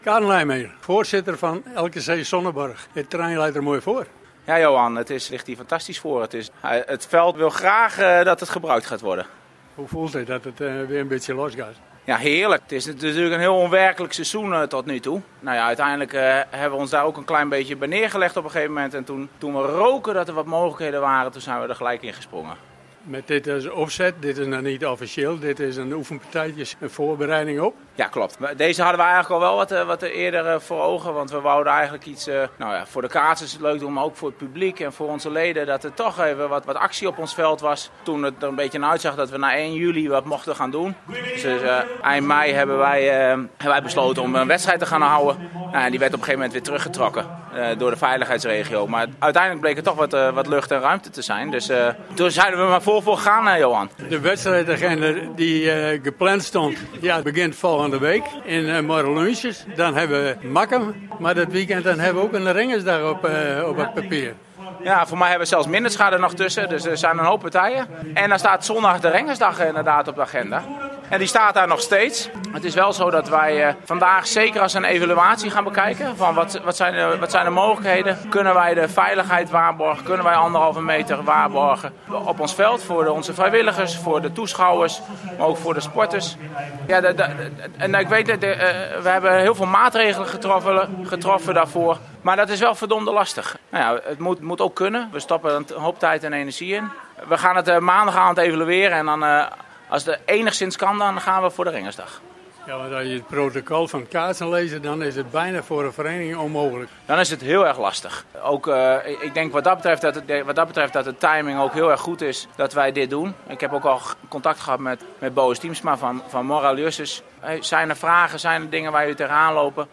LKC gaf op sportpark Sonnenborgh met een trainingswedstrijd voor hoofdklassers de aftrap voor het officiële kaatsseizoen welke volgend weekend van start gaat. Een gesprek